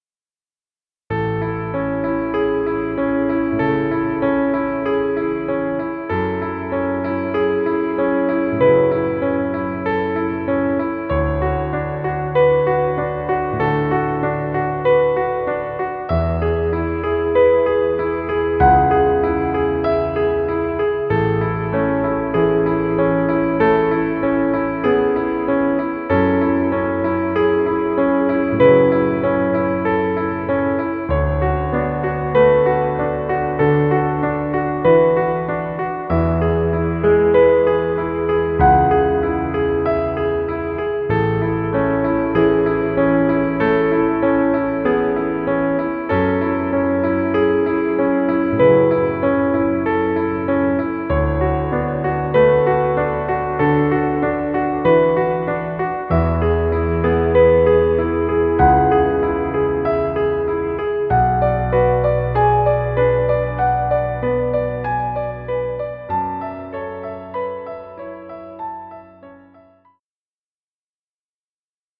Piano music. Background music Royalty Free.